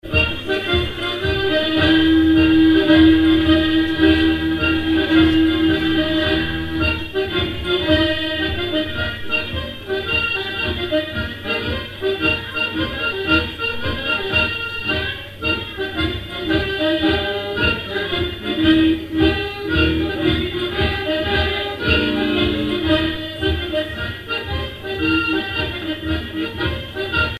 Usage d'après l'informateur gestuel : danse
Genre brève
airs pour animer un bal
Pièce musicale inédite